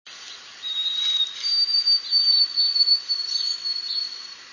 White-throated Sparrow, Hall Avenue, Perth Amboy, New Jersey, 4/13/02, (18kb)